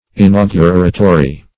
inauguratory.mp3